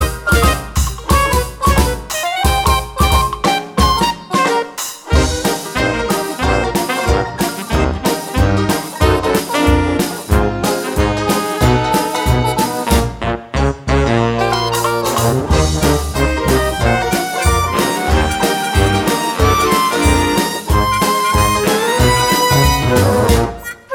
Lead Part Only